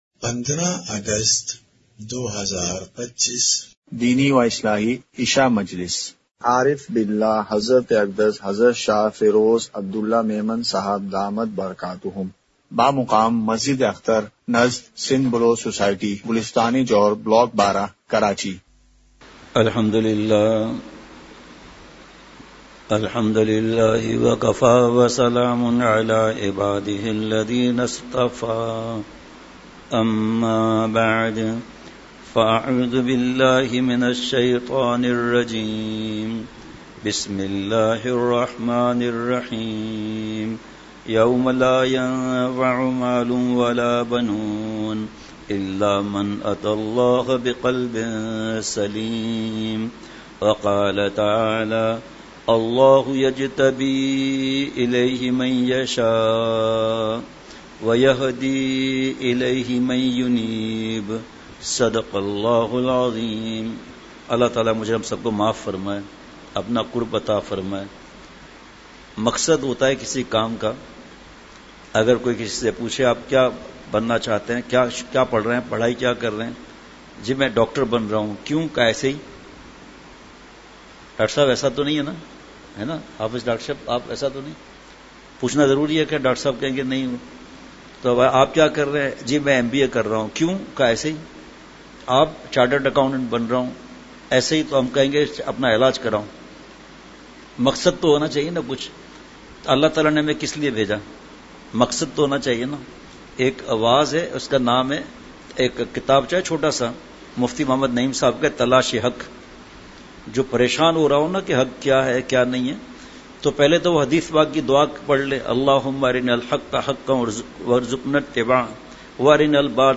مقام:مسجد اختر نزد سندھ بلوچ سوسائٹی گلستانِ جوہر کراچی